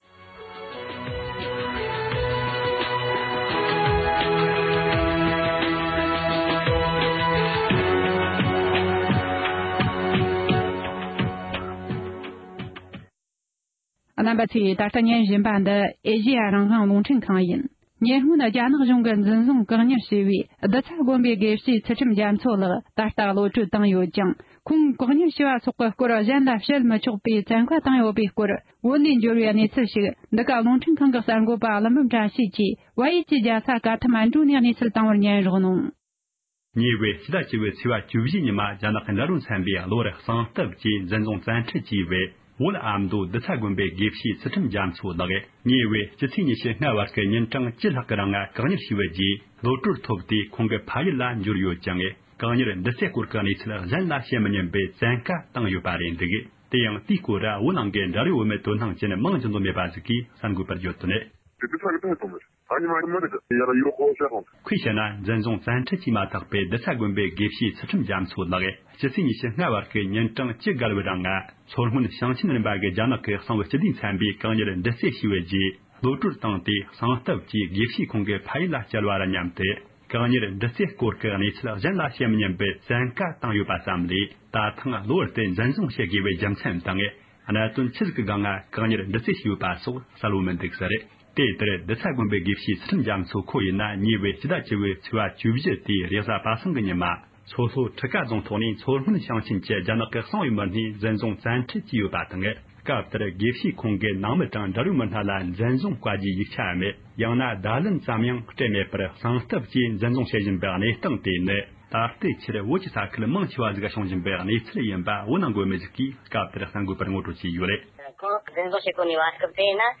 སྒྲ་ལྡན་གསར་འགྱུར།
མིང་འདོན་གནང་འདོད་མེད་པའི་བོད་མི་ཞིག་གིས